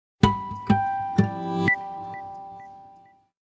гитара
короткие
Интересный звук на смс-сообщение или уведомление.